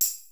T2_perc01.wav